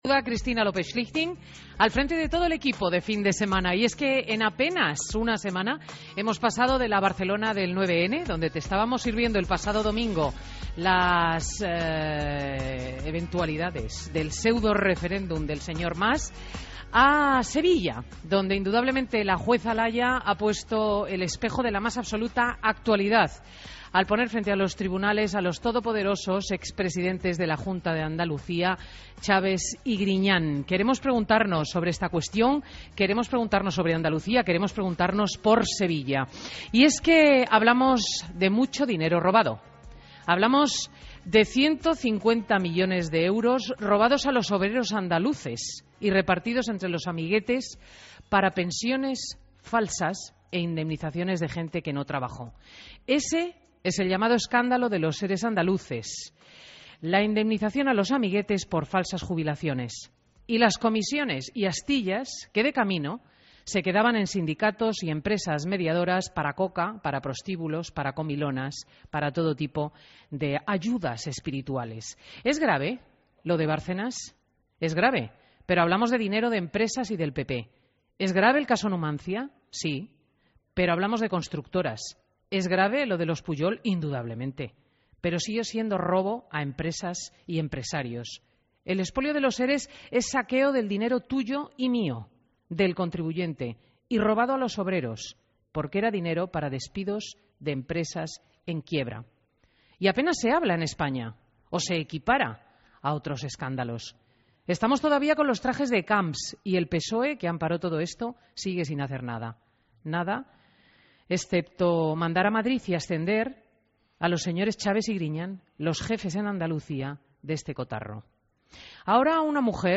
Entrevista a Juan Ignacio Zoido en Fin de Semana COPE